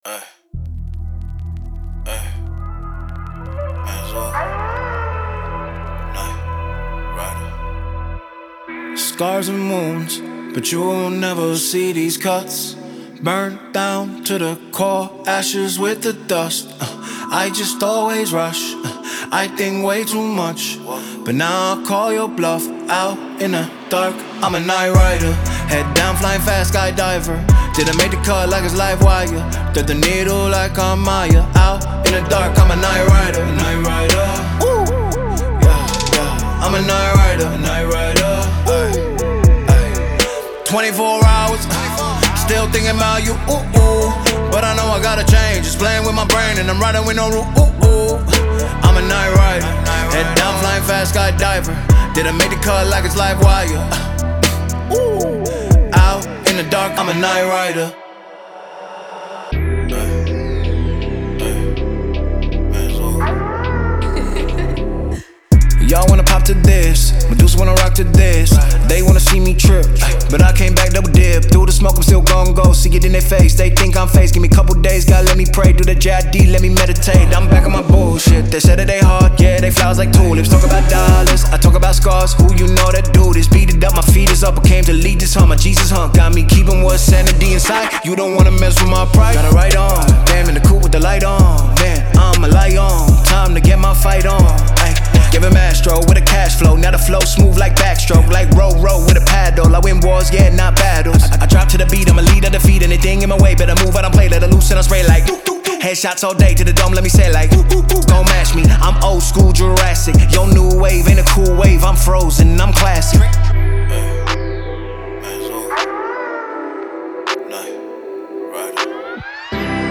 ژانرهای :  هیپ هاپ / رپ